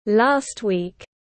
Cuối tuần tiếng anh gọi là last week, phiên âm tiếng anh đọc là /lɑːst wiːk/
Last week /lɑːst wiːk/